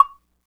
Perc 28.wav